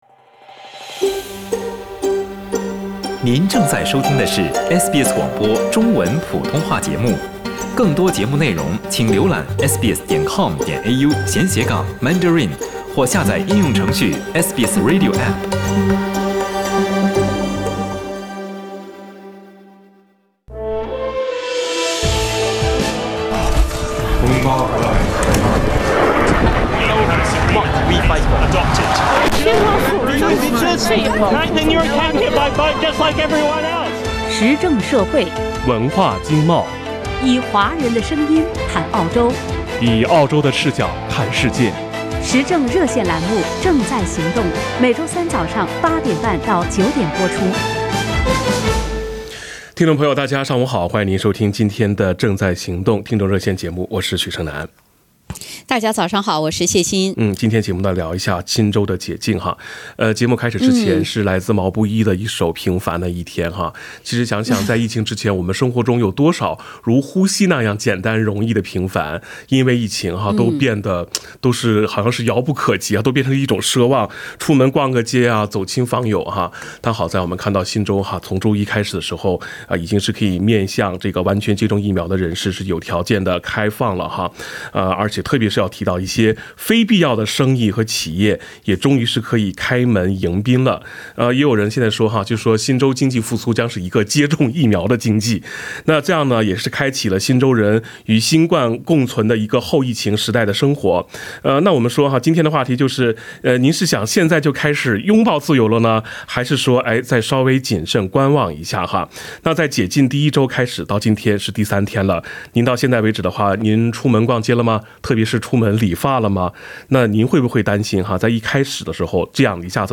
（点击封面图片，收听热线回放）